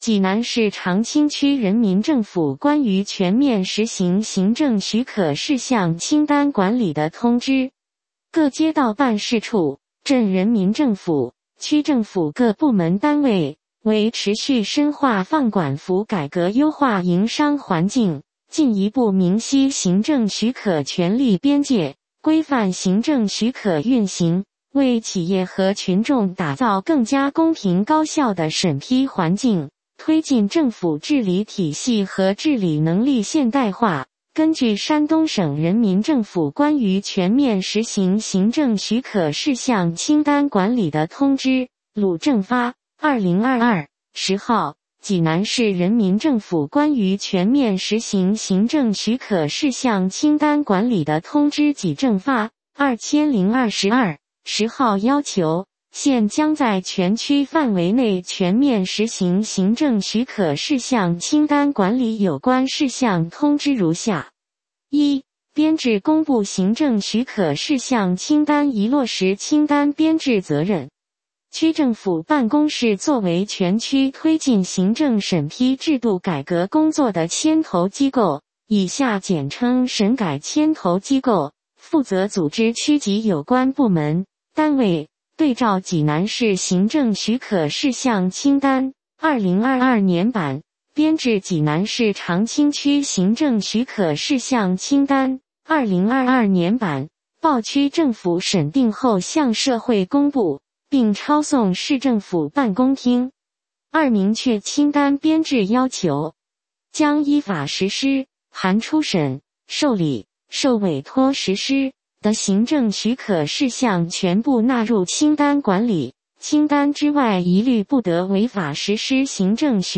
有声朗读：济南市长清区人民政府关于全面实行行政许可事项清单管理的通知